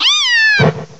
cry_not_litten.aif